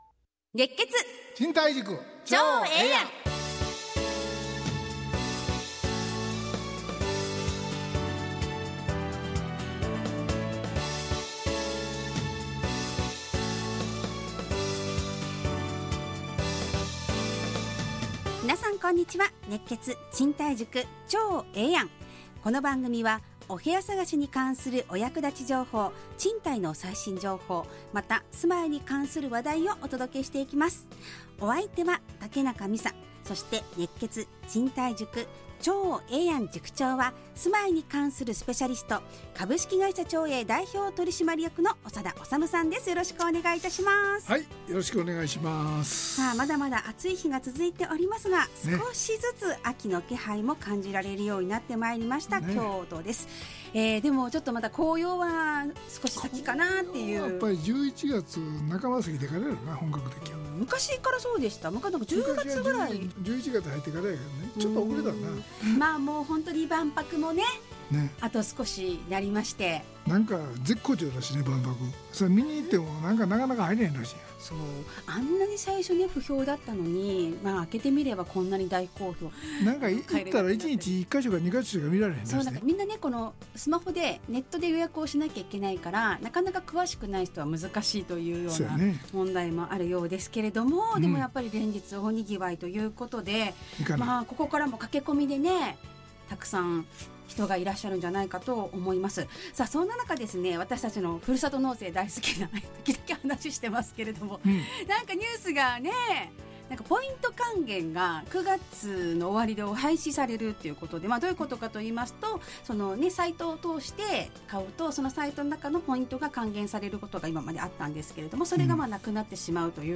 ラジオ放送 2025-09-26 熱血！